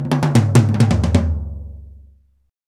Index of /90_sSampleCDs/Roland L-CD701/TOM_Rolls & FX/TOM_Tom Rolls
TOM TOM R07R.wav